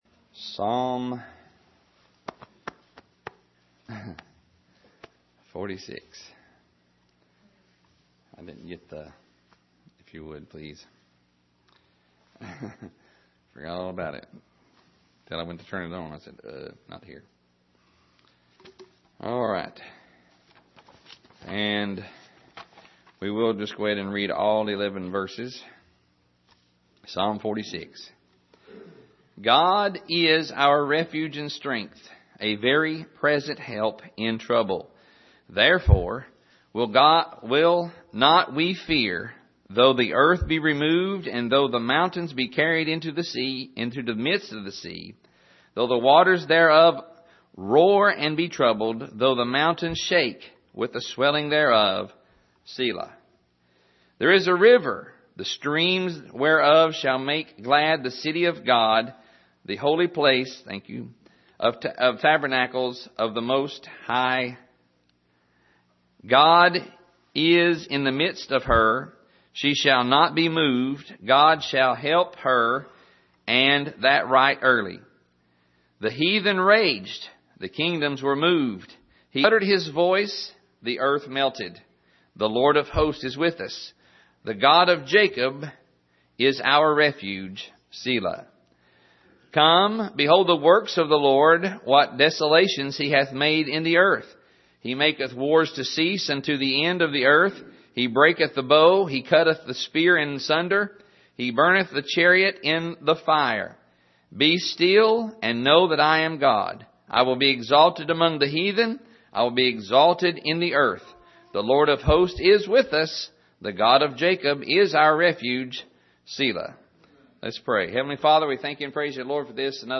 Sermon Archive
Here is an archive of messages preached at the Island Ford Baptist Church.
Service: Sunday Evening